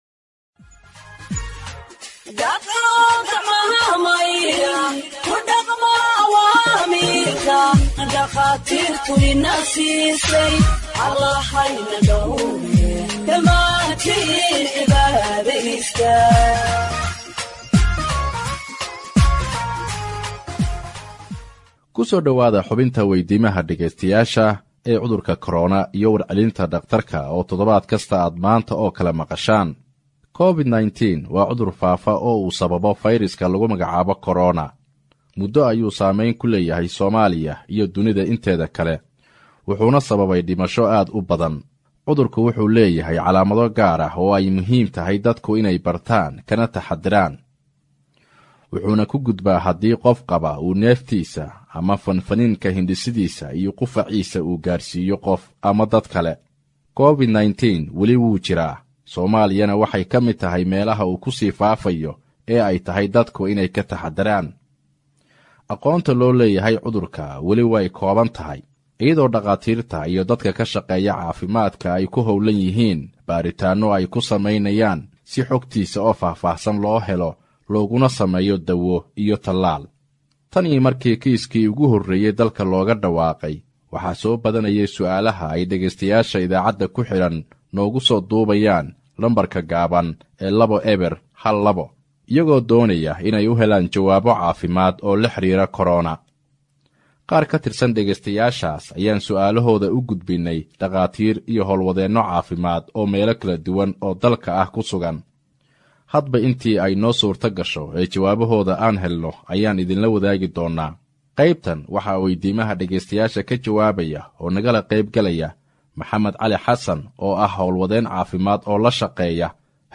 HEALTH EXPERT ANSWERS LISTENERS’ QUESTIONS ON COVID 19 (63)